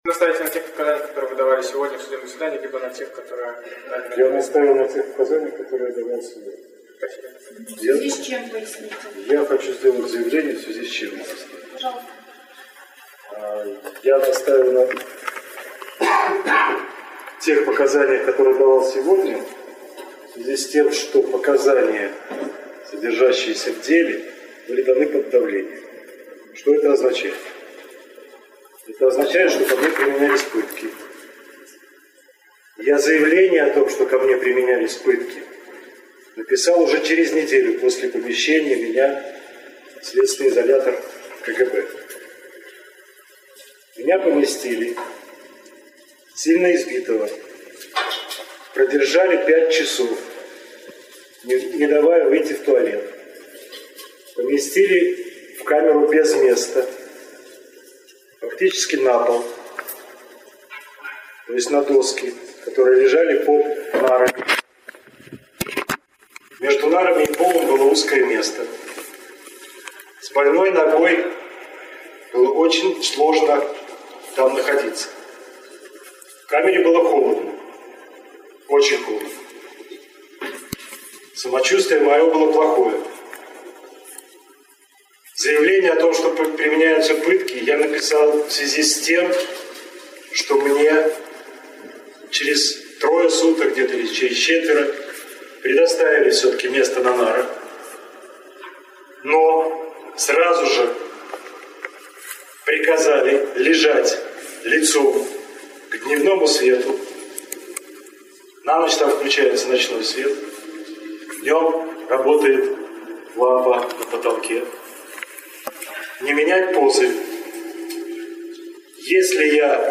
Андрэй Саньнікаў пра катаваньні — прамова на судзе